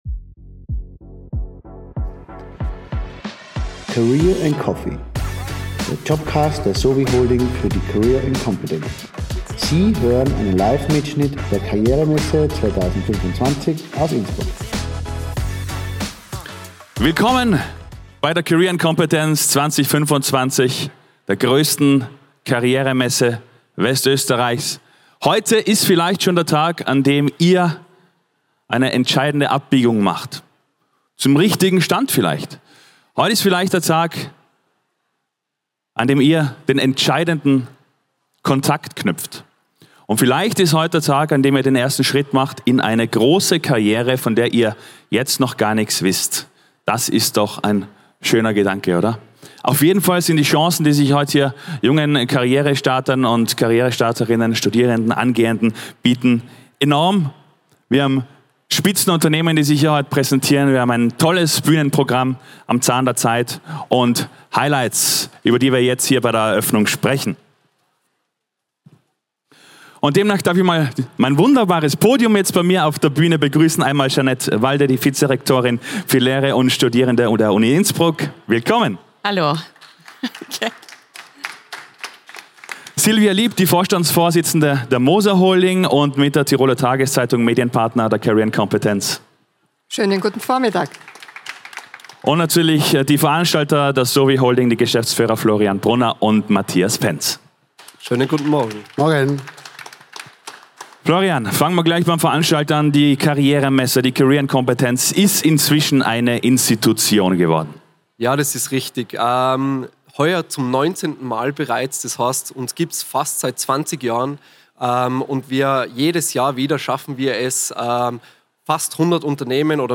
Wir haben alle career talks der Karrieremesse 2025 für dich aufgezeichnet.
Den Auftakt macht die Eröffnungsrede.